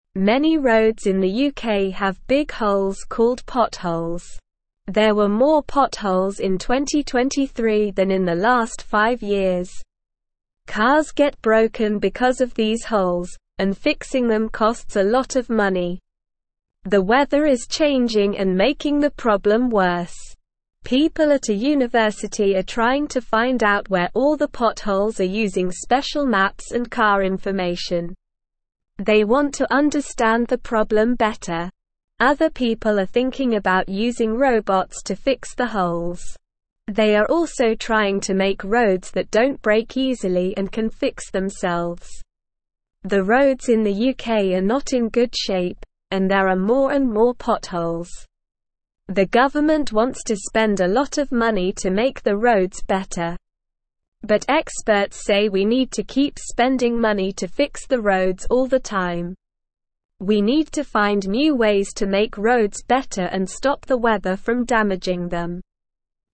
Slow
English-Newsroom-Lower-Intermediate-SLOW-Reading-UK-Roads-with-Holes-Smart-People-Fixing-Them.mp3